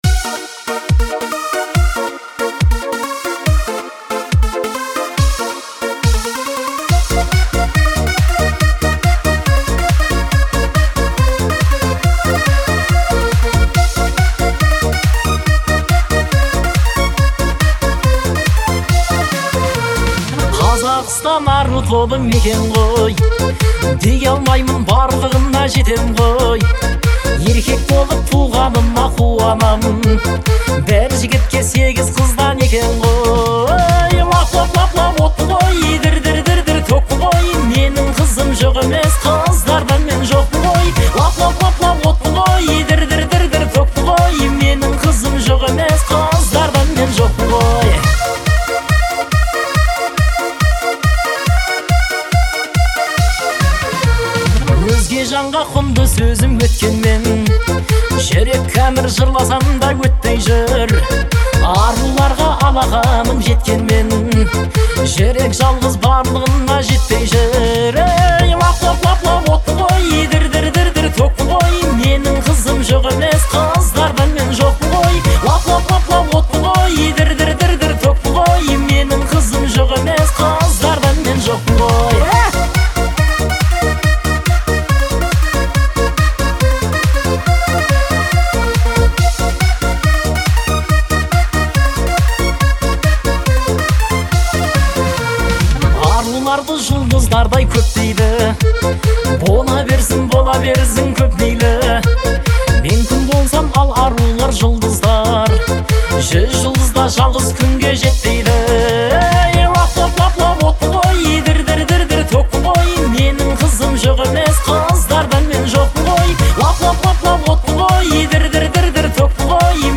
которая сочетает современные ритмы с традиционными мотивами.